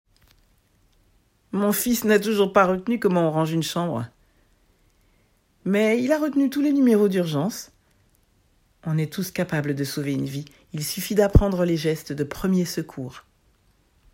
25 - 60 ans - Soprano